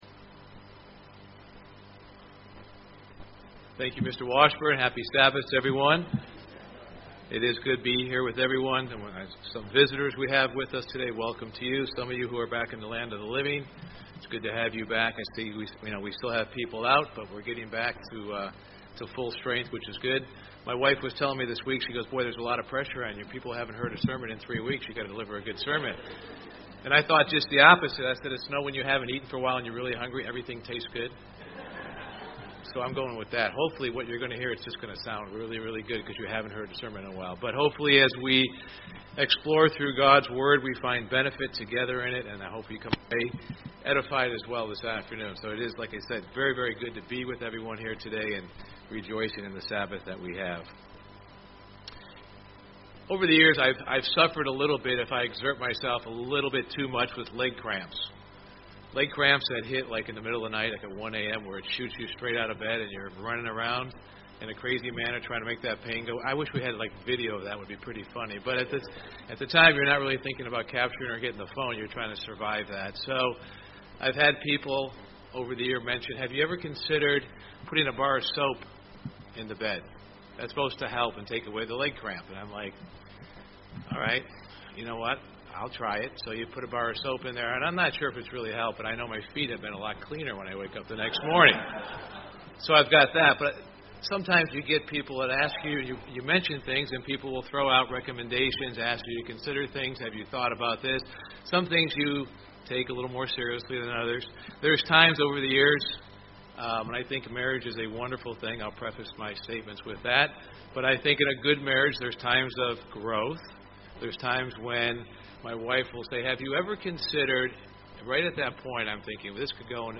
Sermons
Given in Indianapolis, IN